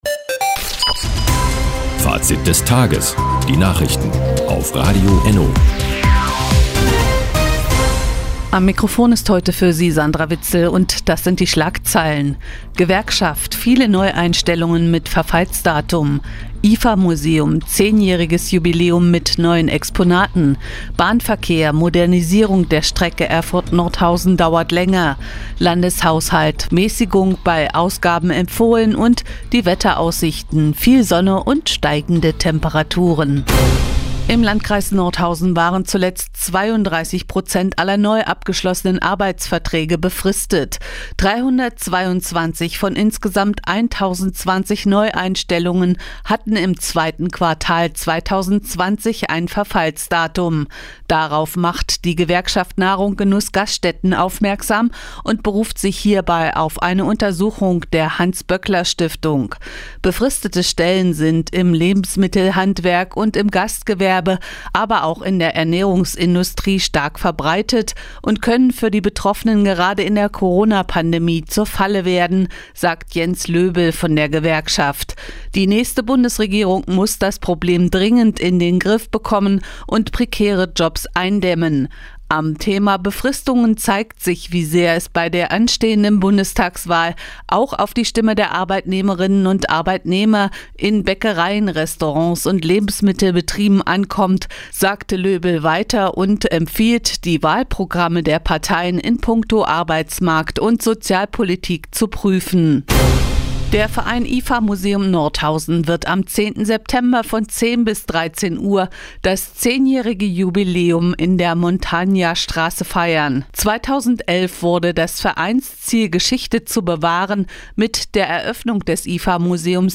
Die tägliche Nachrichtensendung ist jetzt hier zu hören...